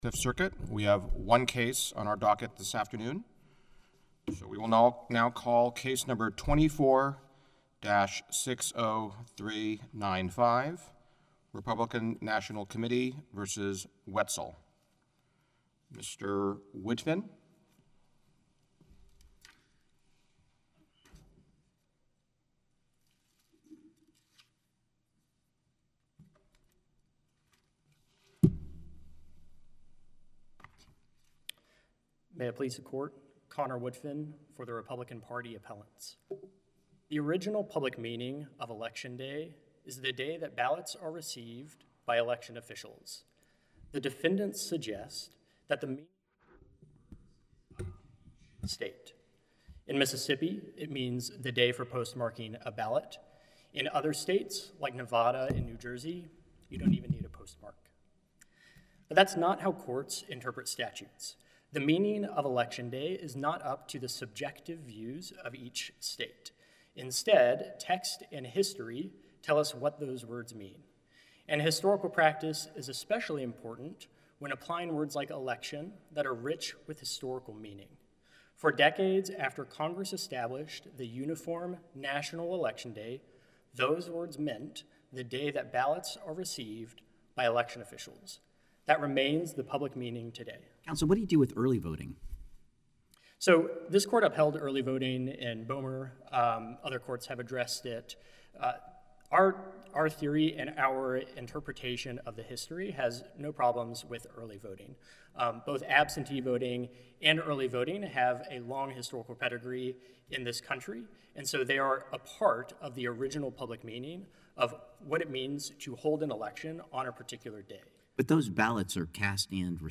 Fifth Circuit holds oral argument
A three-judge panel of the Fifth Circuit holds oral argument.